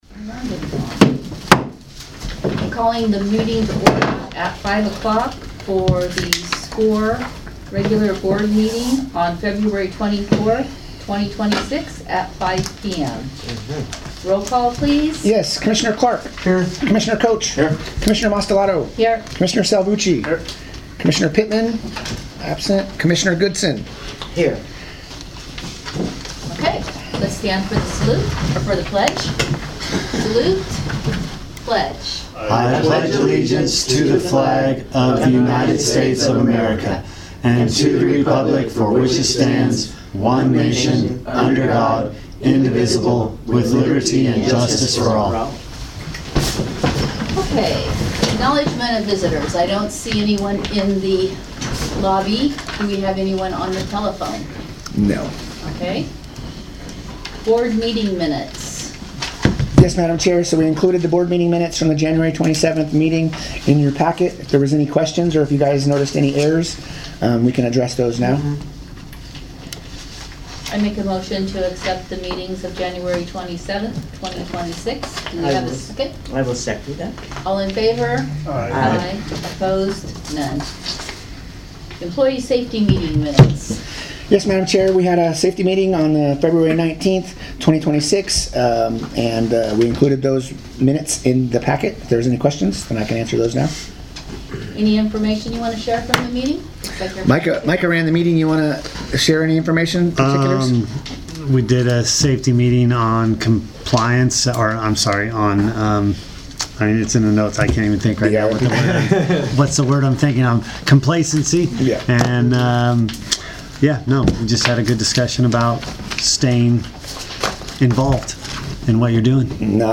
The Sewerage Commission - Oroville Region's Board of Commissioners meets on the fourth Tuesday of each month at 5:00 pm in the board room at its…
Board Meeting